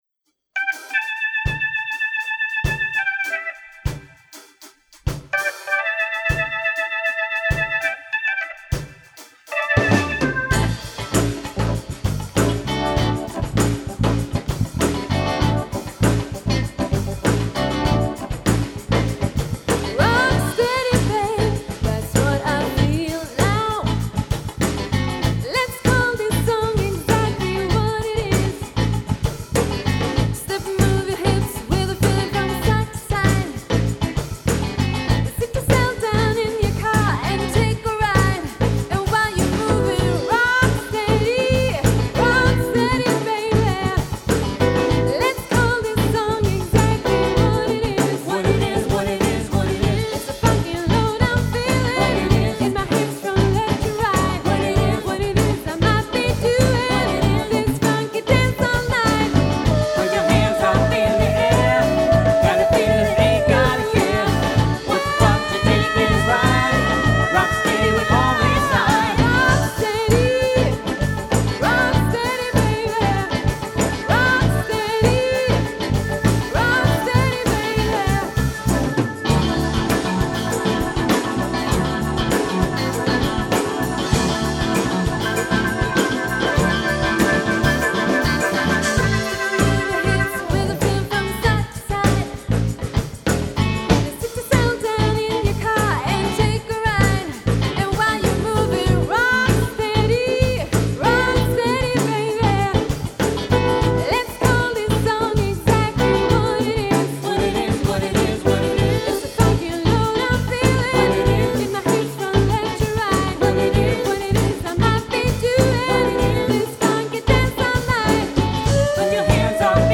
Ett musikprojekt inom Campus Norrköping
trummor
keyboards
sång, gitarr
Då låg musiken närmare funk än dagens soulrepertoar.
med tre sångare i bandet